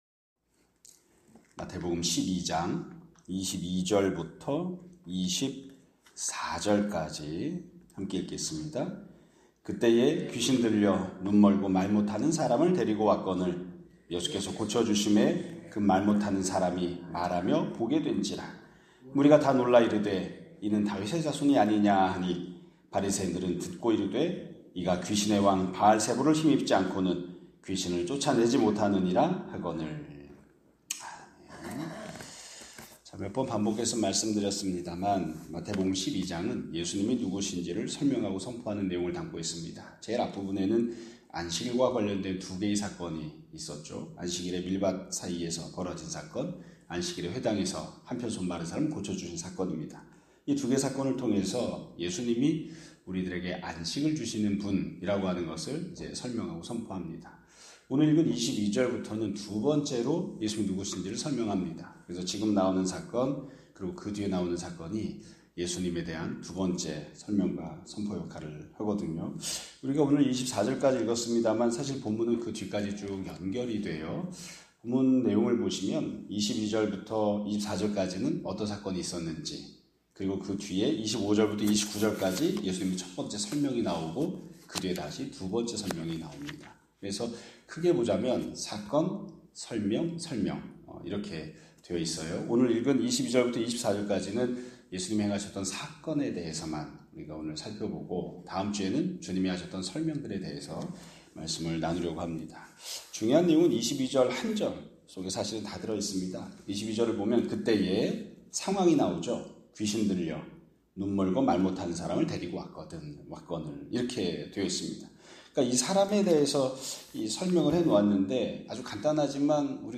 2025년 9월 12일 (금요일) <아침예배> 설교입니다.